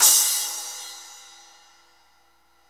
Index of /90_sSampleCDs/Club-50 - Foundations Roland/CYM_xCrash Cyms/CYM_xSplash Cyms